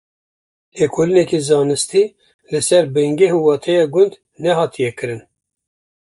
Pronounced as (IPA)
/bɪnˈɡɛh/